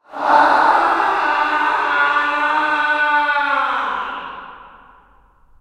2Attack1.ogg